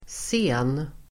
Uttal: [sen:]